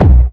Kicks